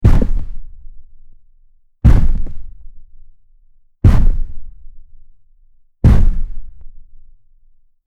Giant Stomping Footsteps 03
Giant_stomping_footsteps_03.mp3